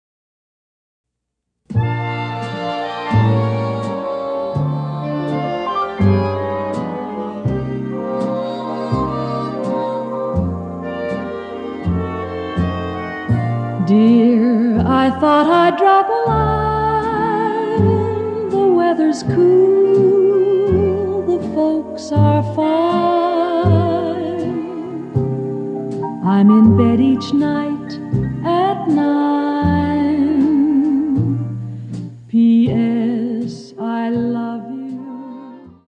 female vocalists
Canadian popular and jazz music